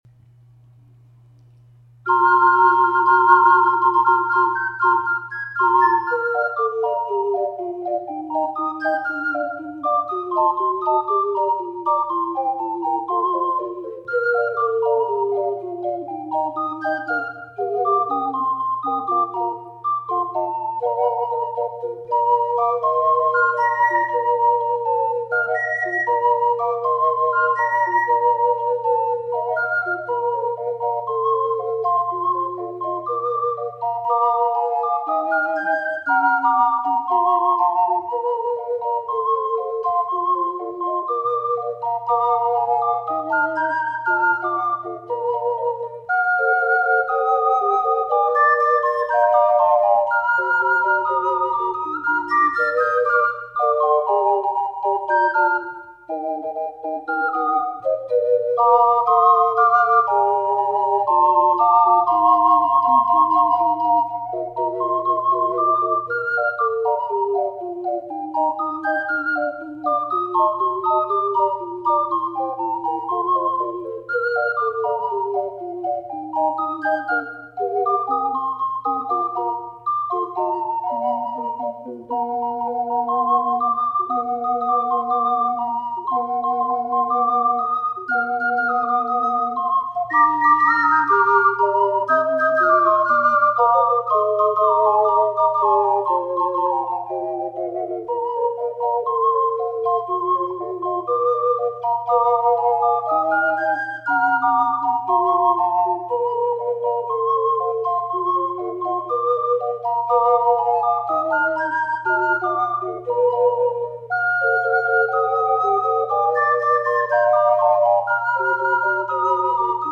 ④BC   楽譜のト長調（G：＃１つ）を変ロ長調（B♭：♭２つ）に転調すれば、全パートがオカリナの音域に収まります。